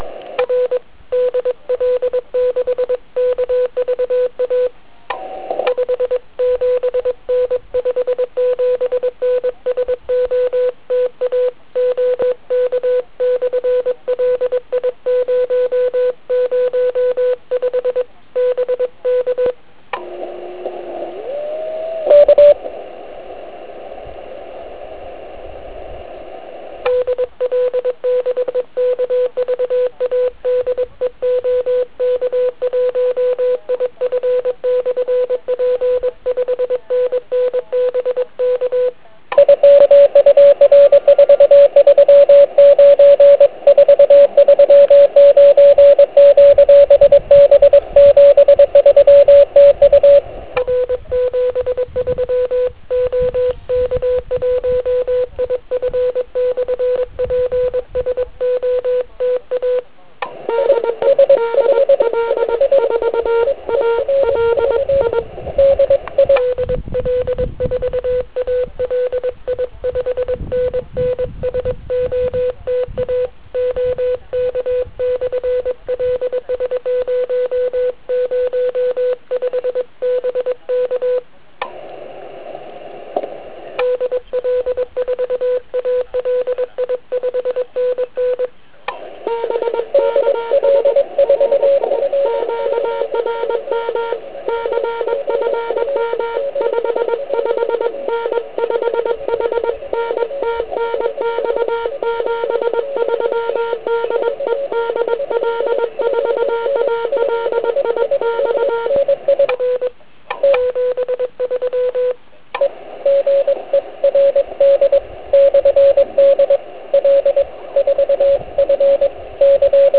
Trošku problém vytvářela silná Es vrstva, která nám sice umožnila i v pásmu 40m se krásně místně spojit, ale bohužel na delší vzdálenosti vznikaly problémy. Je to znát i z ukázek.
Signály ostatních (*.wav 1.5 MB)  Všimněte si, jak na mé 2W odpovídají...